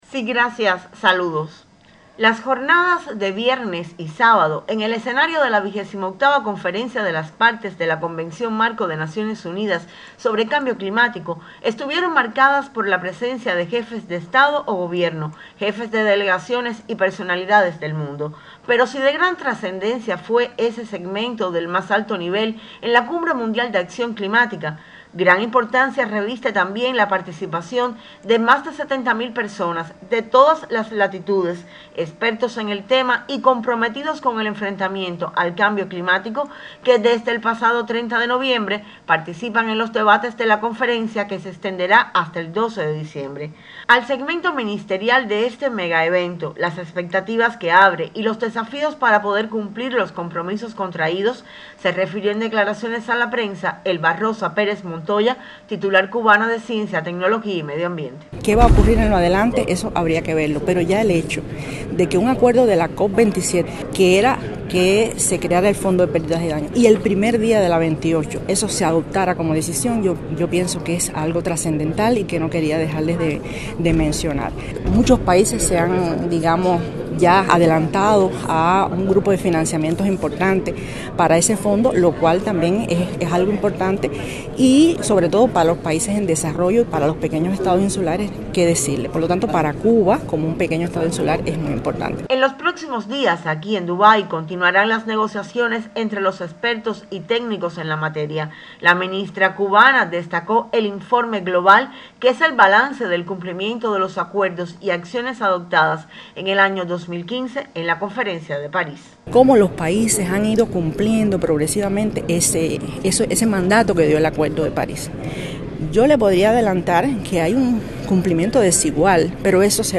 Un encuentro en los concurridos pasillos del Centro de Exposiciones de la ciudad de Dubái, con la ministra cubana de Ciencia, Tecnología y Medio Ambiente (CITMA), Elba Rosa Pérez Montoya, fue la posibilidad de conversar, este sábado en la mañana (hora local), sobre la trascendencia de la COP 28.